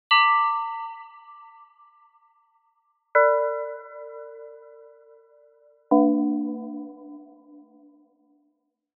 d. Een bel van partialen
Een belklank heeft boventonen, (ookwel partialen genoemd).
Risset Bell ratios
4. Voeg een simpele reverb toe in parallel aan het droge signaal om de klank in een ruimte te plaatsen.